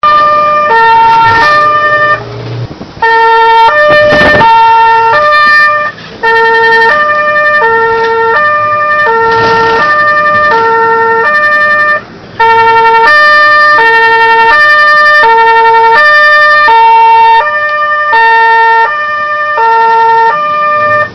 Folgetonhorn Akkon 1
Heute mal dazugekommen das Folgetornhorn vom Akkon 1 aufzunehmen.
Akkon-1 Horn
JUH_Akkon_1_Folgetonhorn.mp3